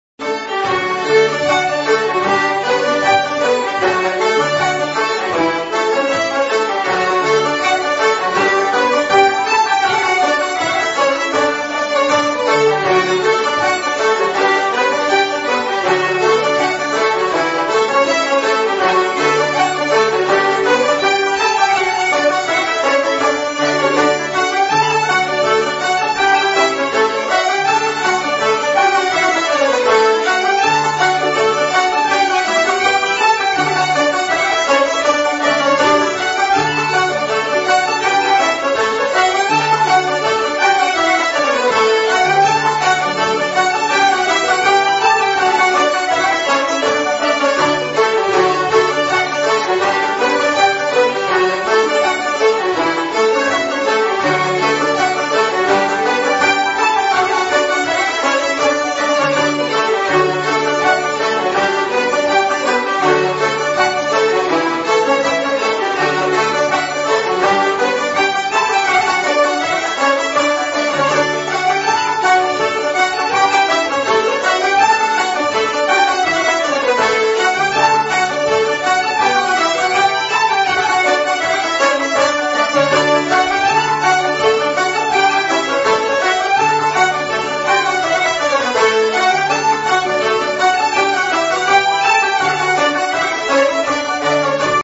14 Derry Hornpipe.mp3